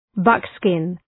Προφορά
{‘bʌk,skın}